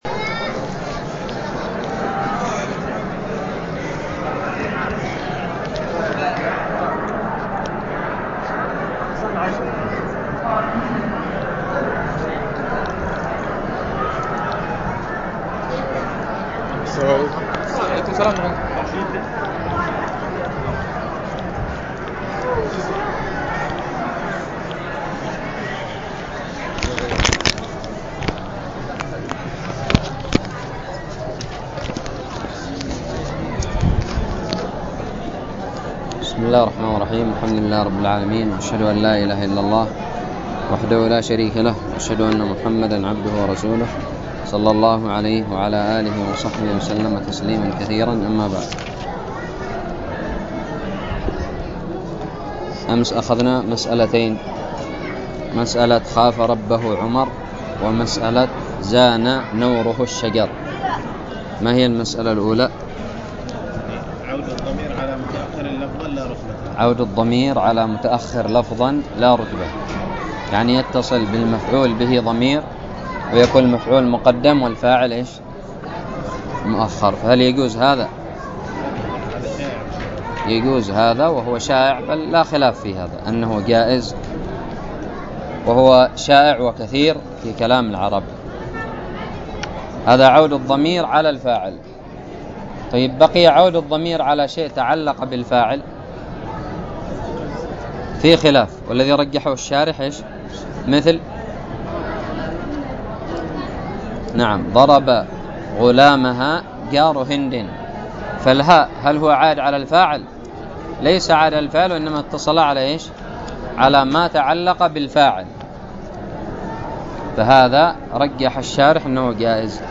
ألقيت بدار الحديث بدماج